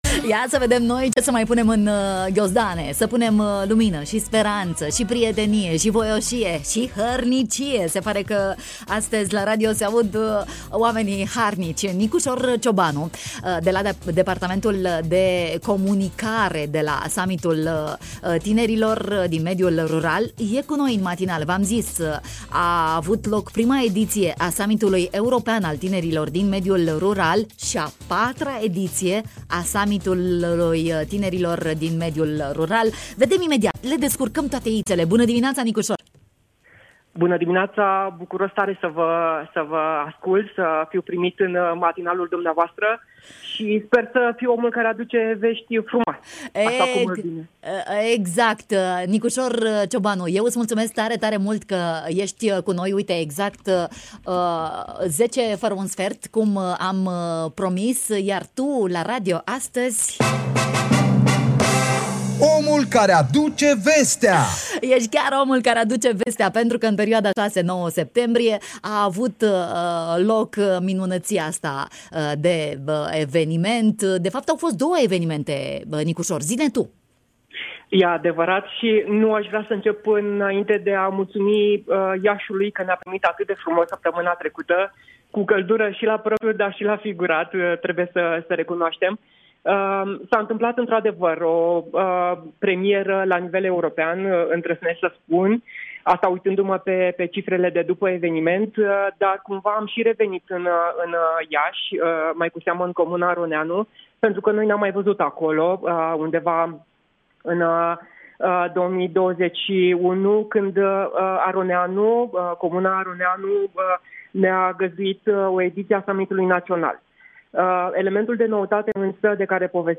în matinalul Radio România Iași: